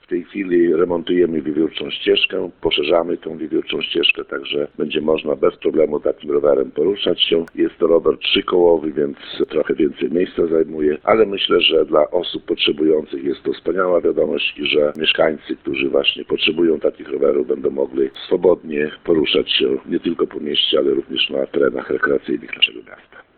Jak dodaje burmistrz Olszewski, w mieście przybywa miejsc, na których będzie można wykorzystać rowery rehabilitacyjne.